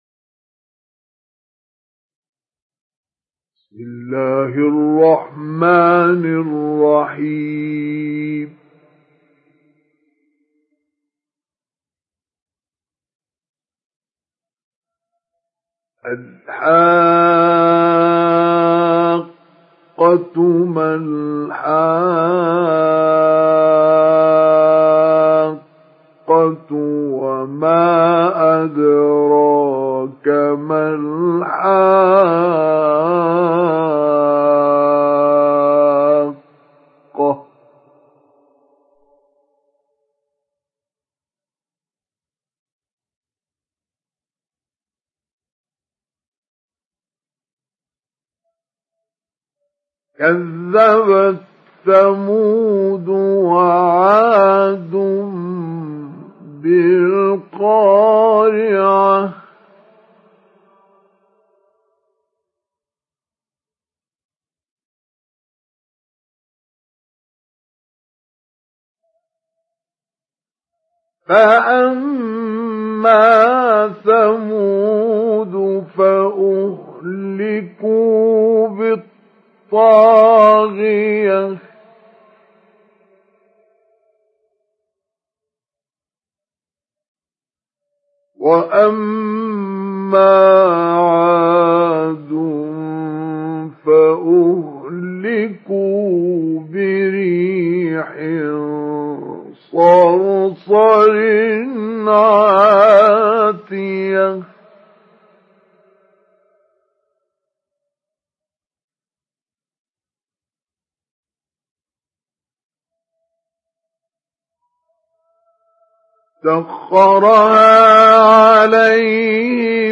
Download Surat Al Haqqah Mustafa Ismail Mujawwad